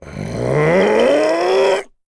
Kaulah-Vox_Casting1.wav